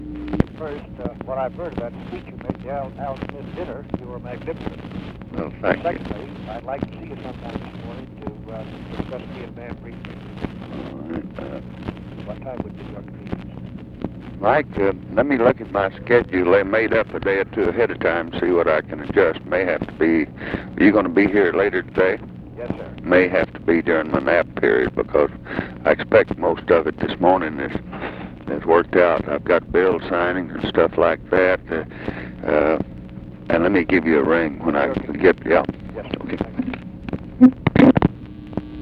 Conversation with MIKE MANSFIELD, October 17, 1968
Secret White House Tapes